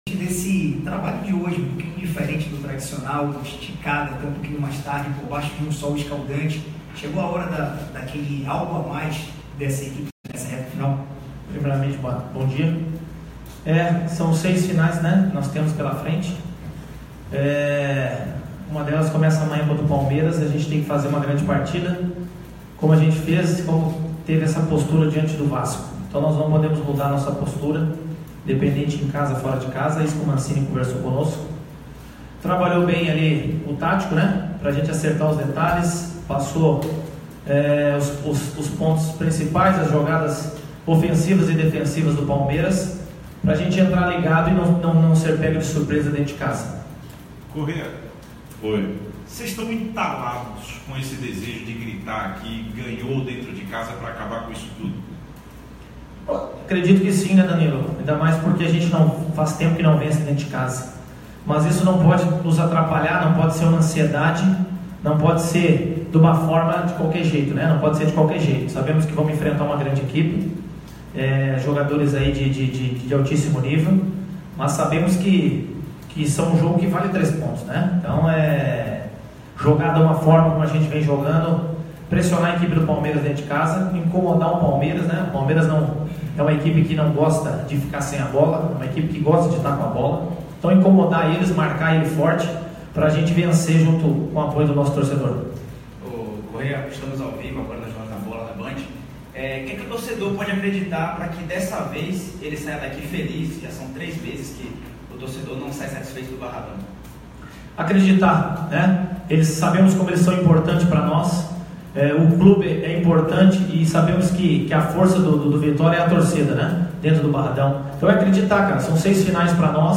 O jogador foi alvo de entrevista coletiva publicada nesta terça-feira em uma das paginas das redes sociais do Esporte Clube Vitória. Nele o jogador falou da ansiedade de vencer uma partida fundamental depois de três meses sem triunfo no Barradão.